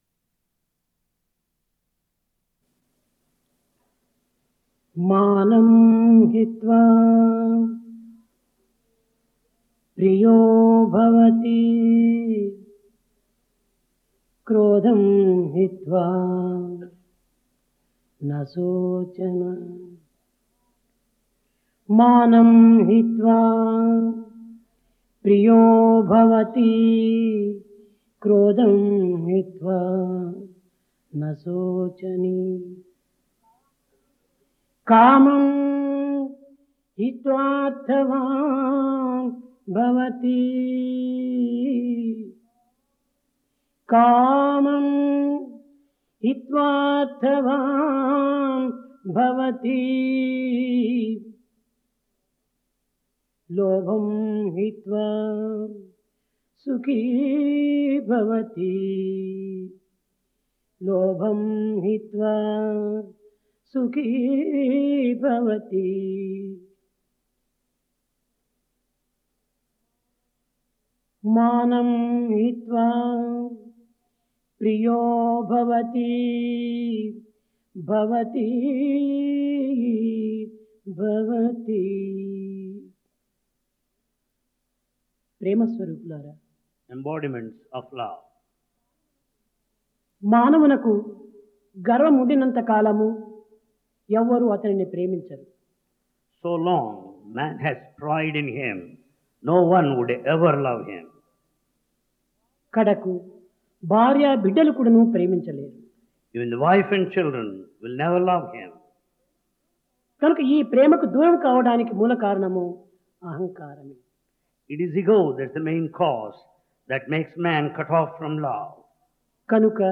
Discourse
Occasion World Youth Conference